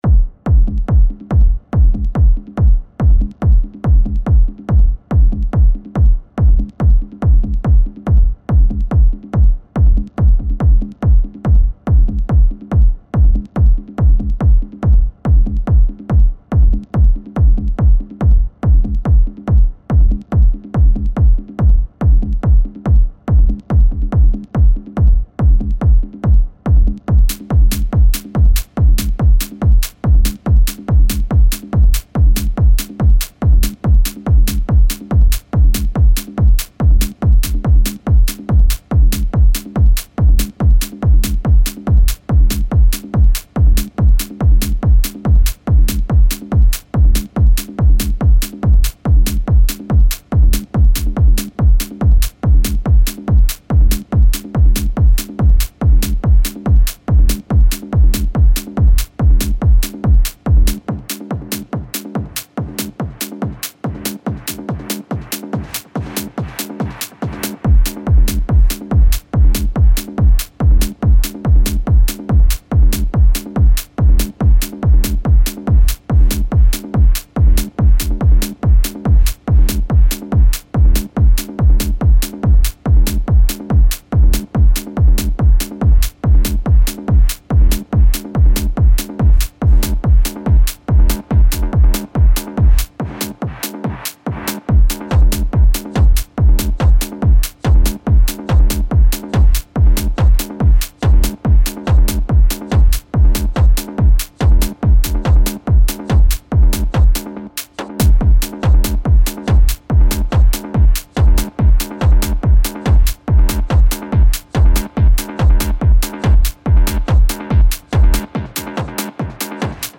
hard hitting snare. hard af.
“drop” lacks impact but Im not EDMing, this time Im a bit deeper into overall sound design.